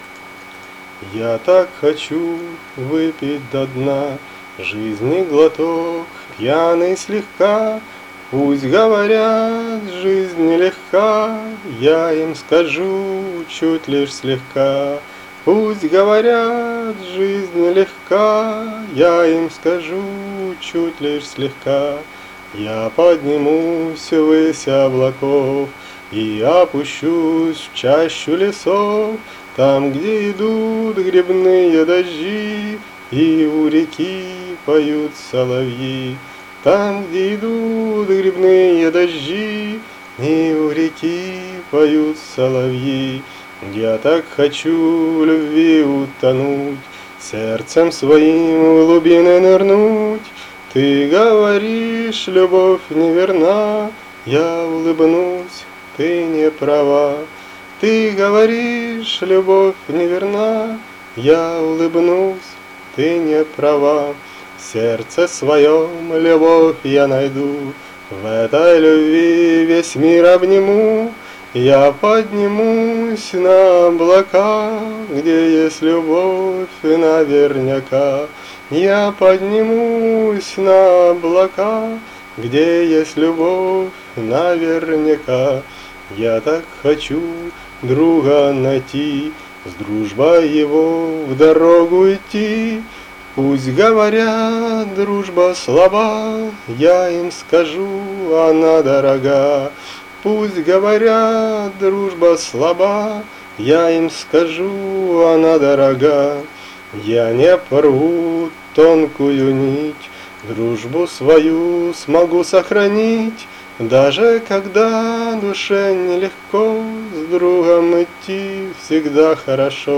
Рубрика: Поезія, Авторська пісня
ВИКОНАННЯ ЦІЄЇ ПІСНІ НАБАГАТО КРАЩЕ ВІД ПОПЕРЕДНІХ - ВИ СТАЛИ БІЛЬШ СМІЛИВІ І ВПЕВНЕНІ 12